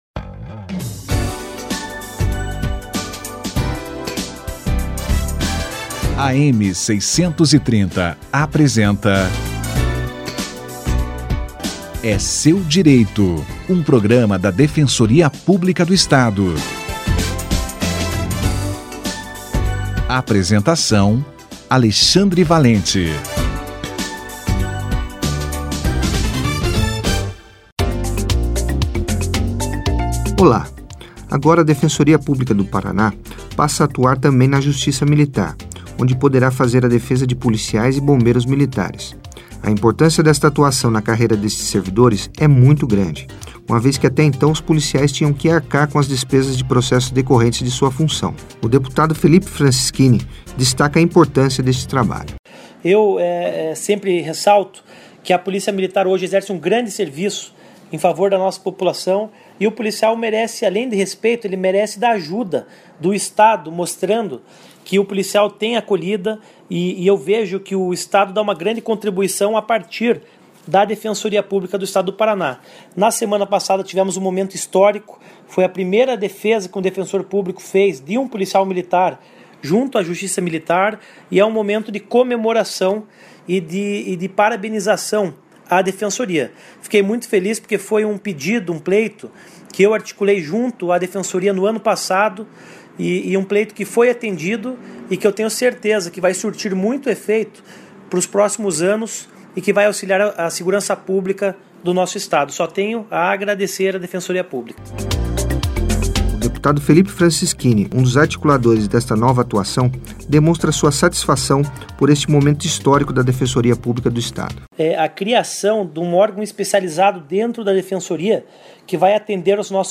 12/12/2017 - Defensoria Pública passa a atuar na defesa de policiais e bombeiros militares - Entrevista Dep. Felipe Francischini